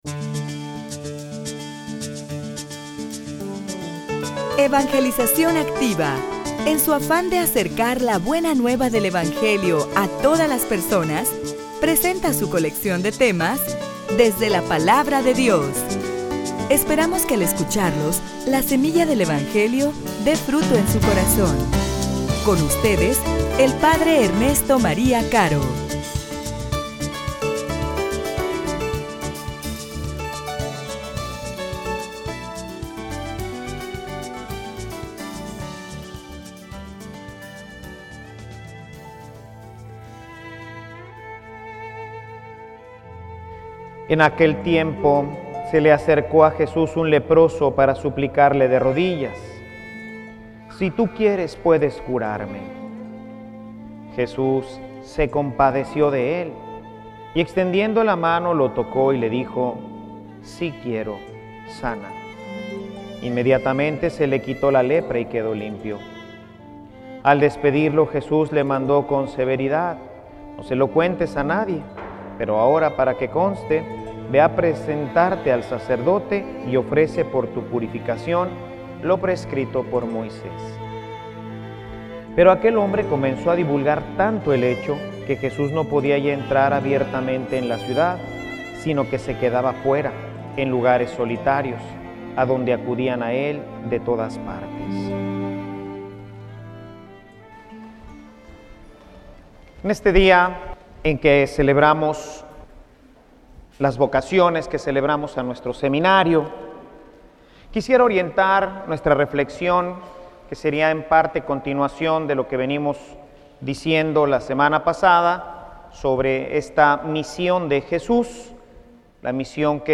homilia_A_quien_enviare.mp3